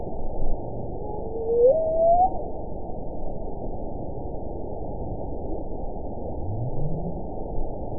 event 921718 date 12/17/24 time 22:44:31 GMT (11 months, 2 weeks ago) score 9.35 location TSS-AB02 detected by nrw target species NRW annotations +NRW Spectrogram: Frequency (kHz) vs. Time (s) audio not available .wav